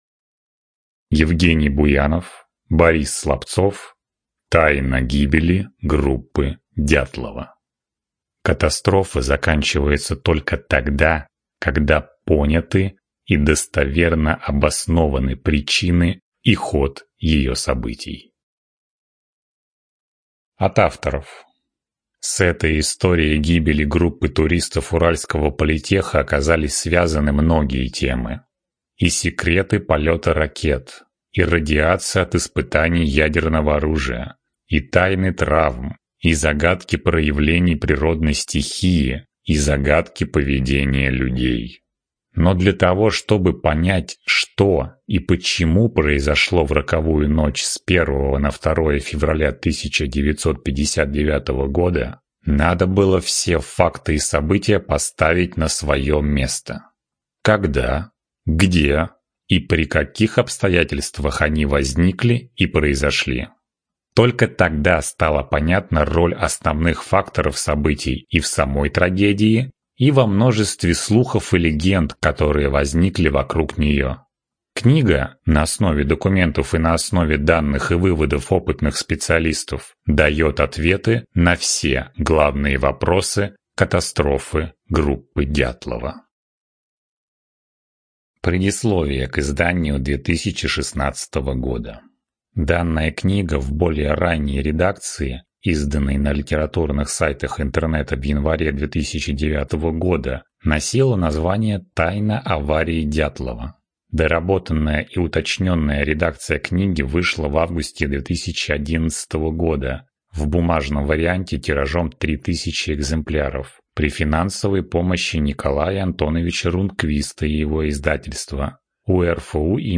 ЖанрДокументальная проза